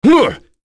Roi-Vox_Attack5.wav